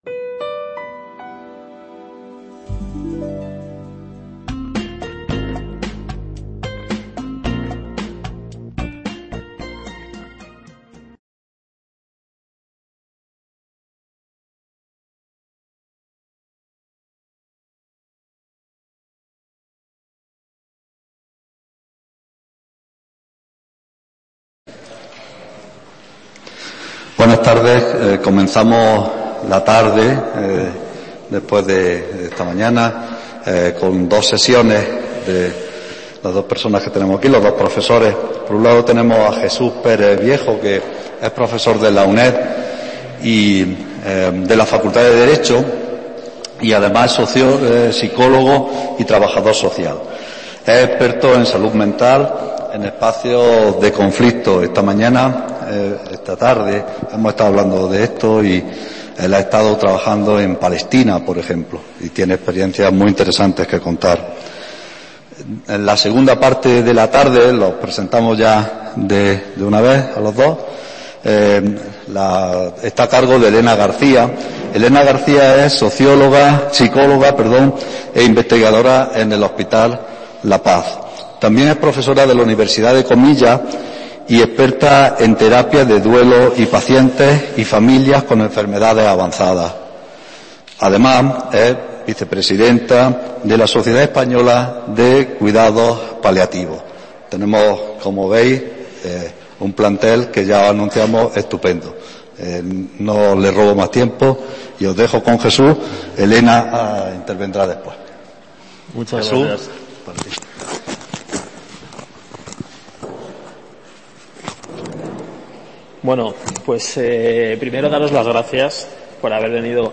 que se celebra en Alcalá la Real (5 a 7 de julio de 2021) en los Cursos de Verano de la UNED